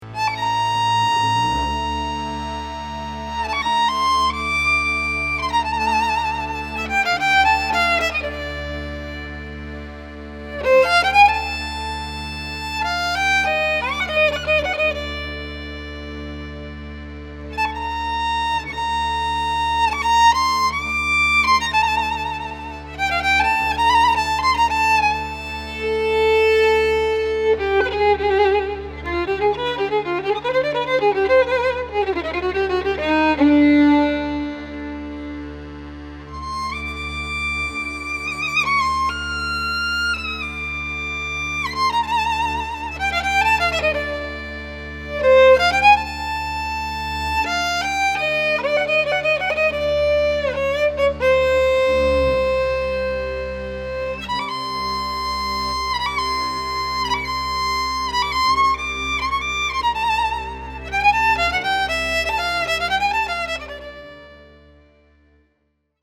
Recorded at Bay Records, Berkeley, CA, May 2003
Genre: Klezmer.